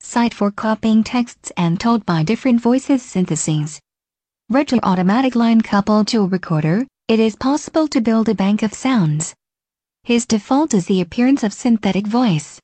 Voix de synthèses - Prim 76
syntetic-voice.mp3